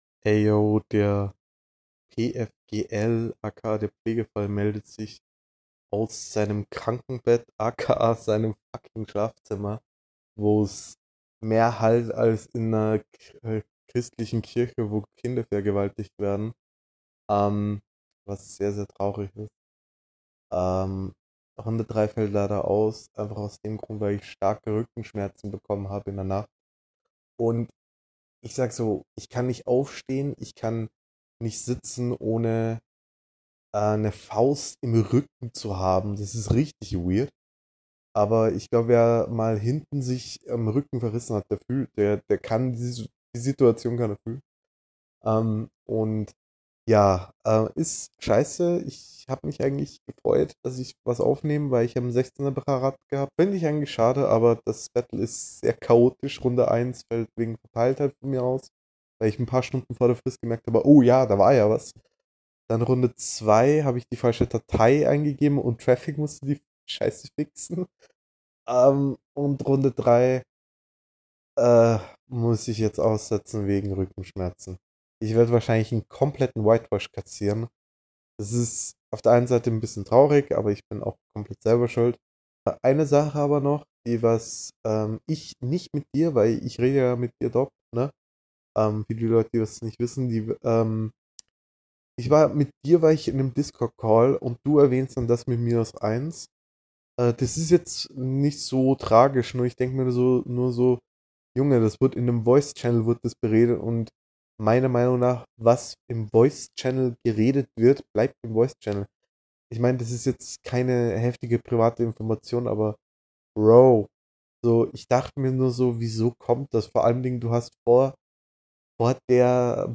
Flow: Text: Soundqualität: Allgemeines: er redet einfach für 3 minuten über sein leben oder so …
D: DDD: DDDDD: reden reden reden kein rap :c nix bewerten nix punkte ja da …